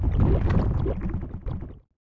attakc_act_1.wav